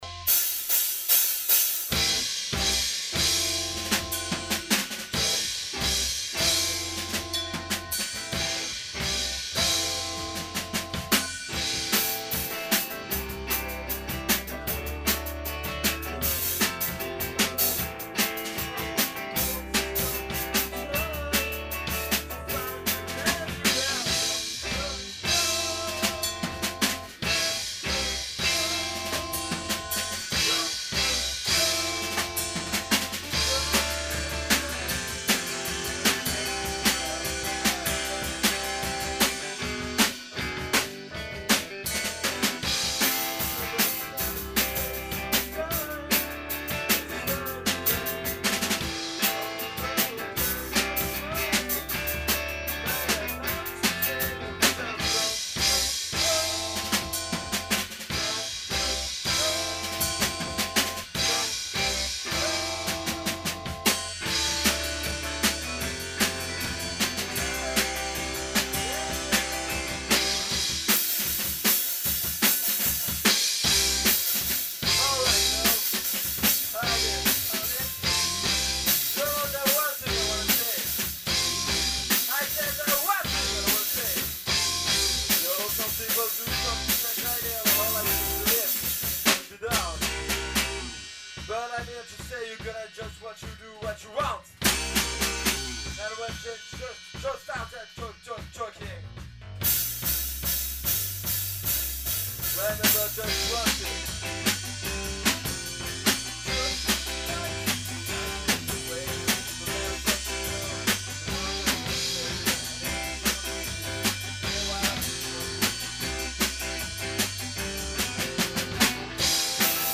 garage rock
aperçu rapide enregistré en répèt